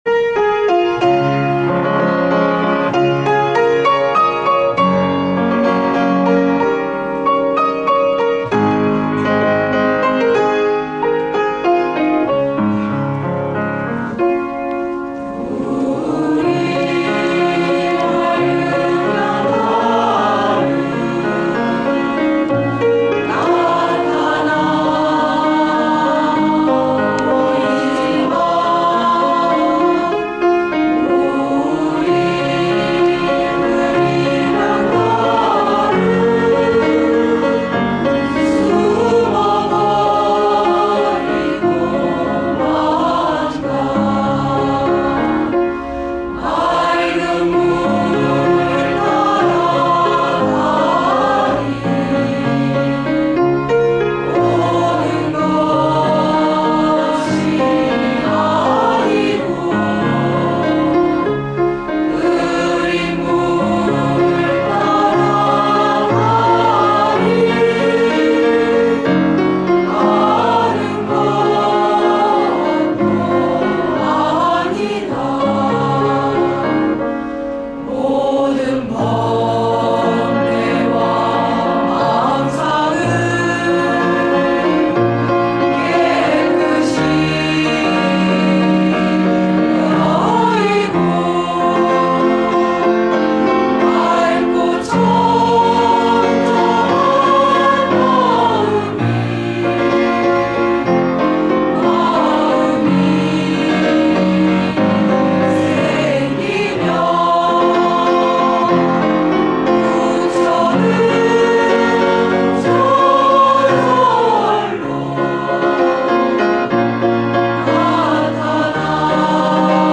This second song is called That Mind, Just As It is. It’s sung by the nuns of Hanmaum Seon Center, and is actually just a practice version.(They’ll kill me if they know I posted this!)